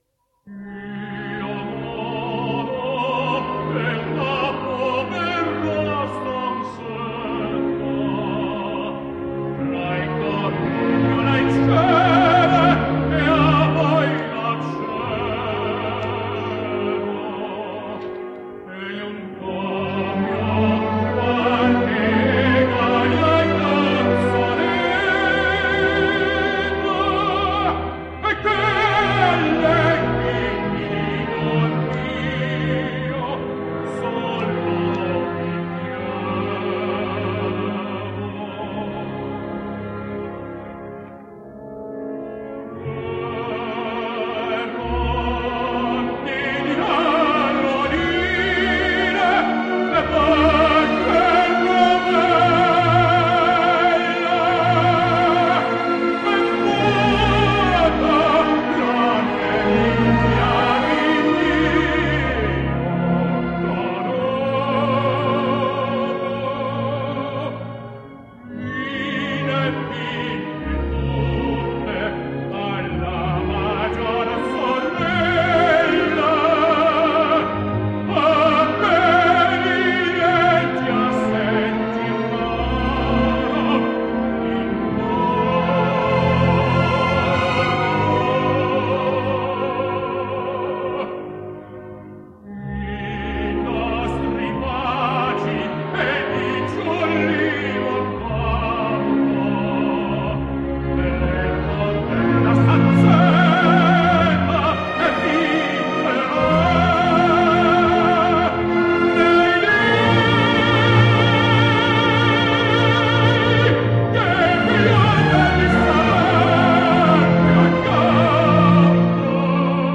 For though, essentially a lyric tenor, Vanzo is quite capable indeed, was extremely popular in Italian verismo roles.
Io Non Ho + Duet / Boheme / 1975 – Alain Vanzo